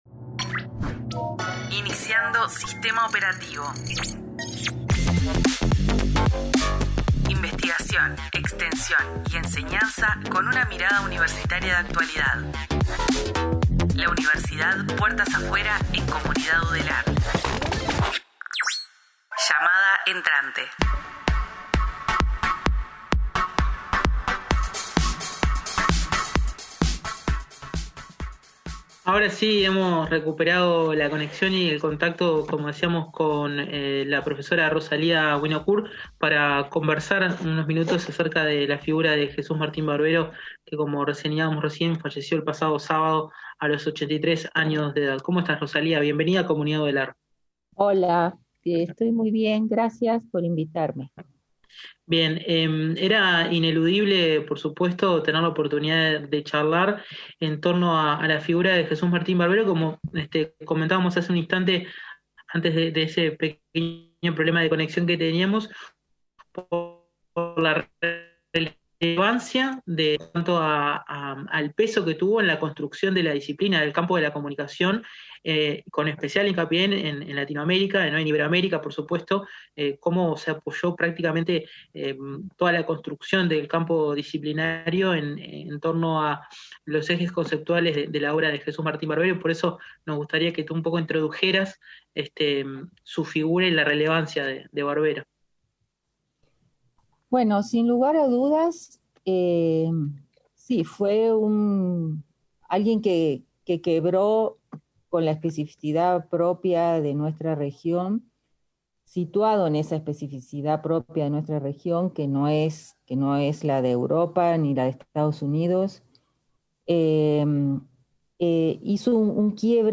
Comunidad Udelar, el periodístico de UNI Radio. Noticias, periodismo e investigación siempre desde una perspectiva universitaria.